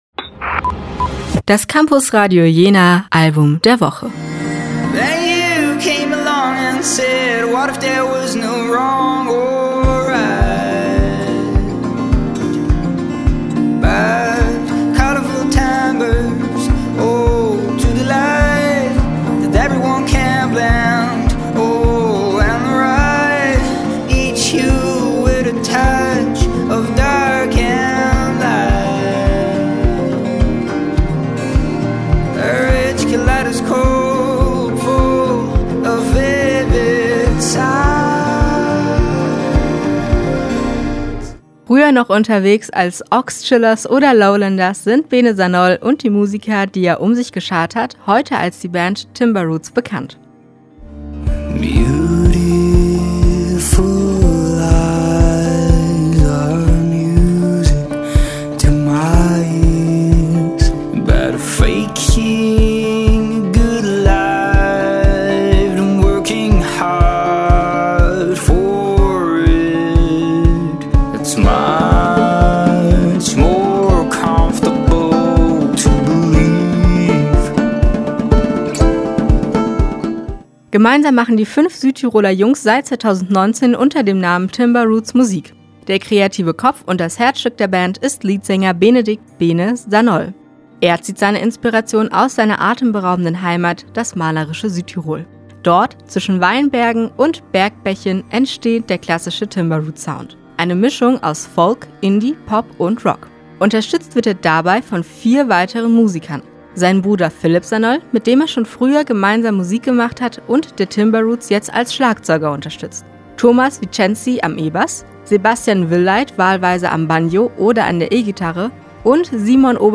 Timbreroots mit ihrem Album “Heartbeat Chronicles” – ein Album, das ans Herz geht – und somit verdient das Campusradio Jena Album der Woche.